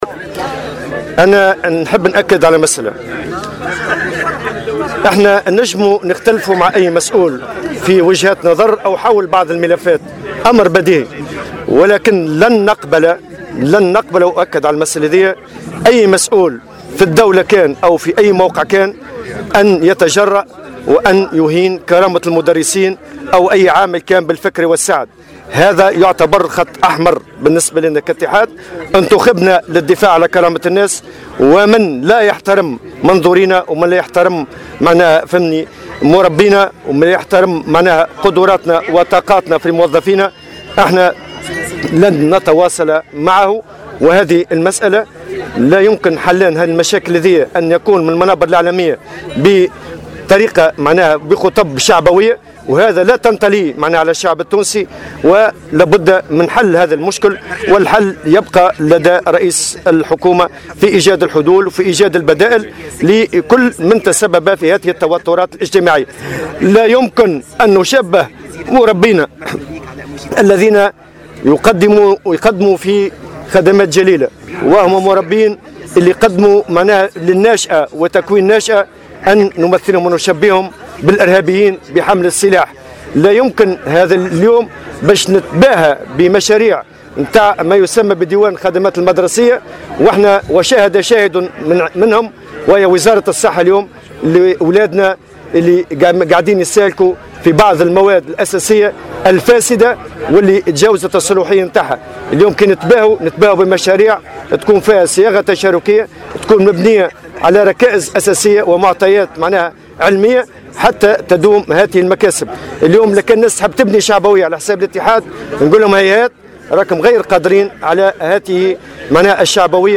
وقال في تصريح صحفي لمراسل "الجوهرة اف أم" في صفاقس، على هامش تدشين باخرة تحمل اسم الزعيم النقابي الحبيب بن عاشور، إنه لا يمكن حل مشاكل القطاع انطلاقا من منابر إعلامية ومن خلال خطابات شعبوية، بحسب تعبيره.